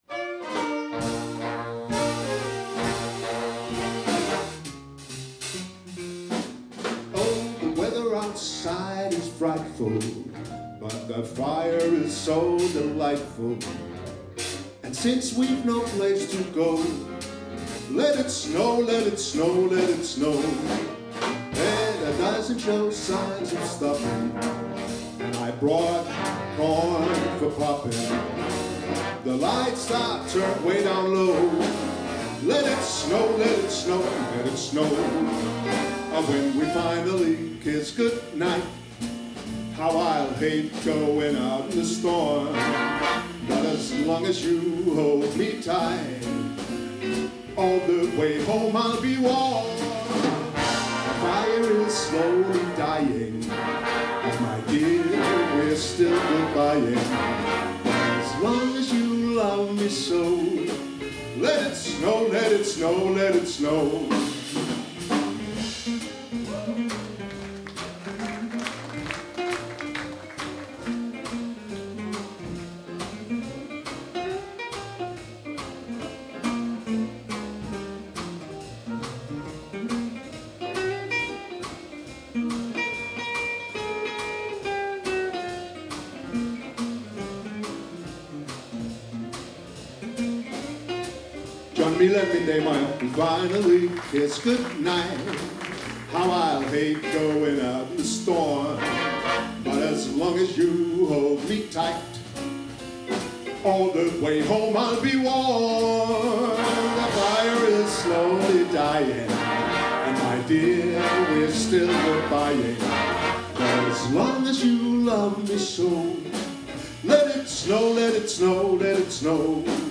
Vi garanterer, at I kommer i julestemning når I hører de jazzsvingende juleklassikere, flere af dem med vokal-indslag.
Udstyret er én digital stereo mikrofon, ikke en studieoptagelse !